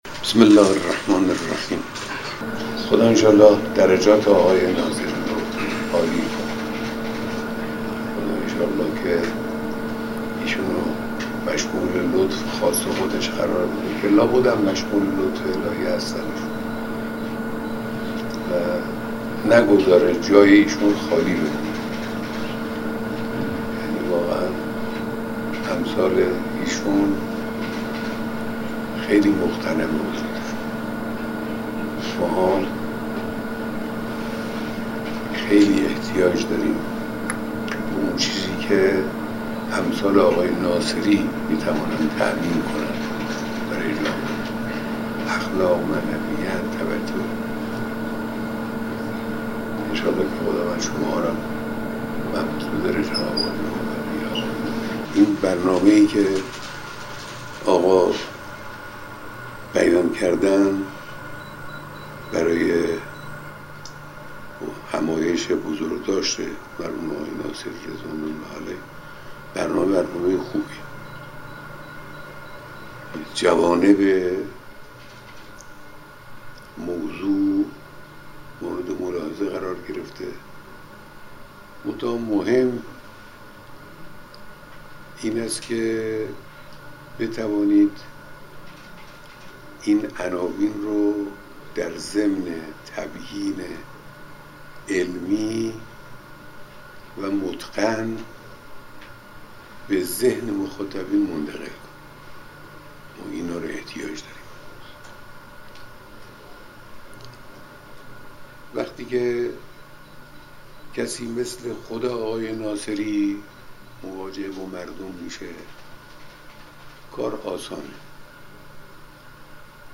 بیانات در دیدار دست‌اندرکاران همایش بزرگداشت مرحوم آیت‌الله ناصری